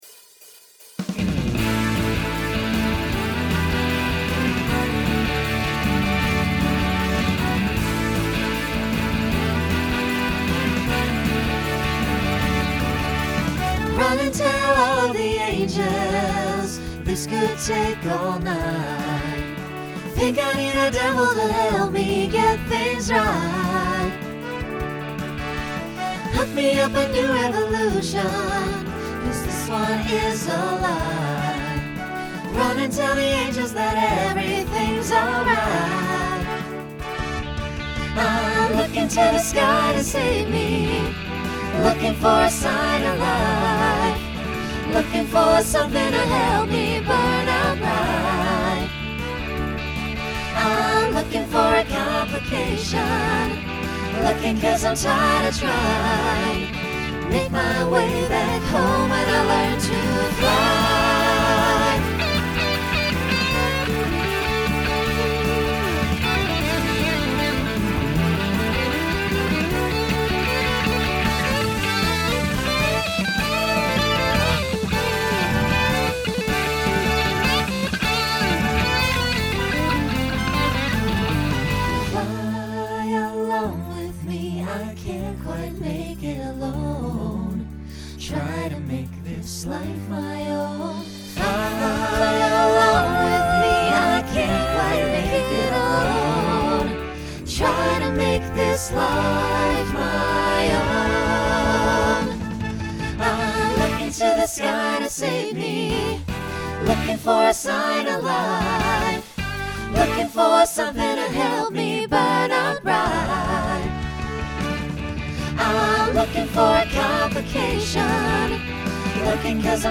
New SSA voicing for 2024.